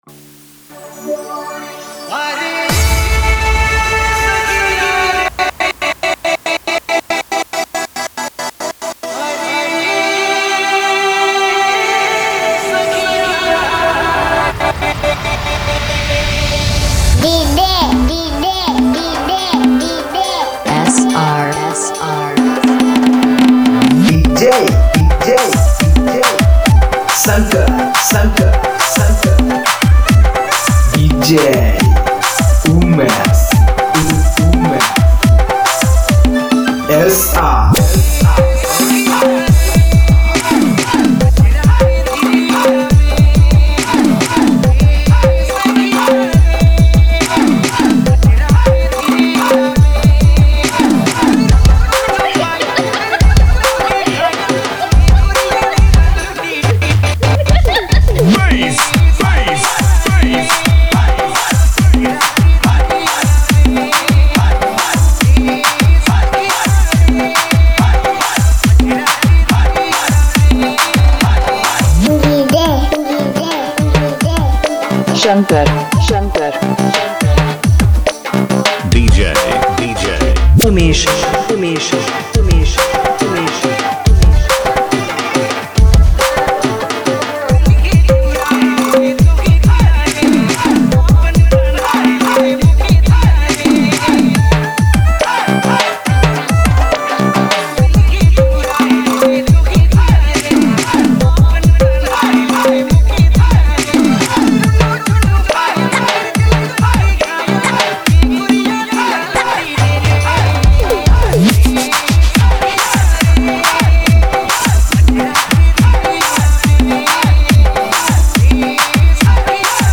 high-energy Trance MX remix